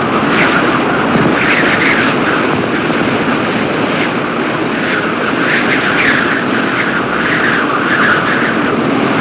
Whipping Wind Sound Effect
Download a high-quality whipping wind sound effect.
whipping-wind.mp3